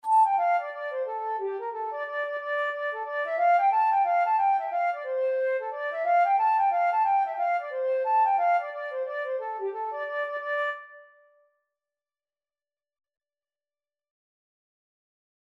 Flute version
2/4 (View more 2/4 Music)
G5-A6
D minor (Sounding Pitch) (View more D minor Music for Flute )
Instrument:
Traditional (View more Traditional Flute Music)